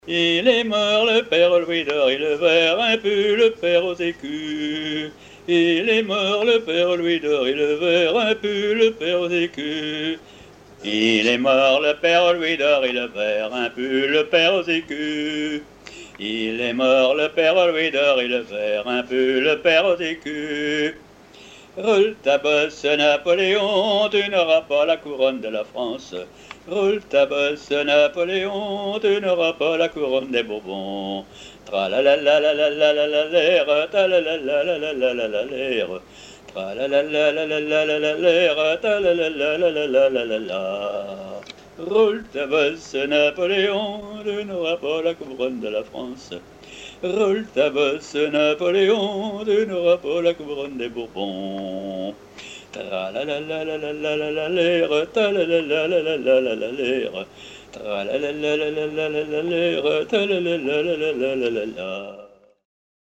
Couplets à danser
branle : avant-deux
Pièce musicale éditée